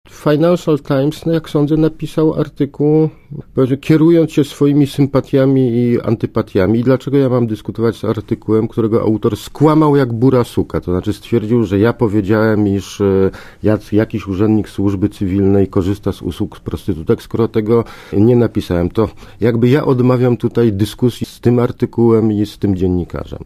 Mówi Ludwik Dorn